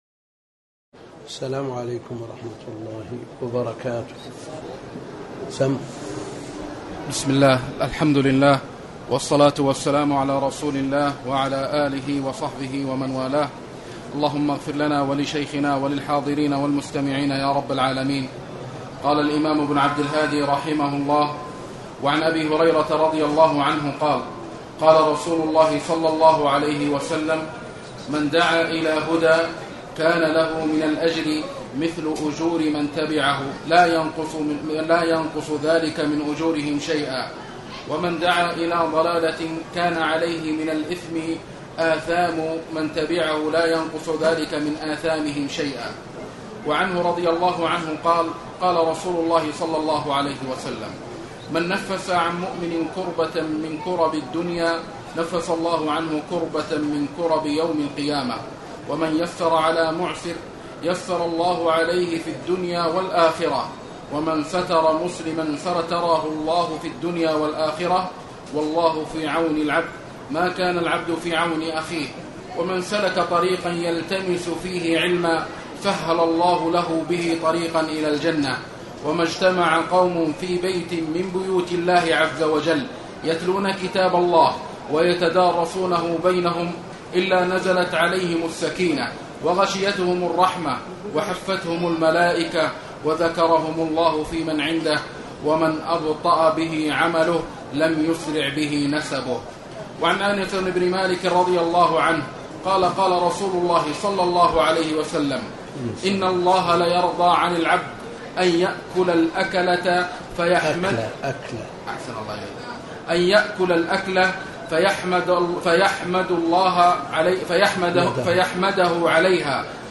تاريخ النشر ٨ ذو القعدة ١٤٣٩ هـ المكان: المسجد الحرام الشيخ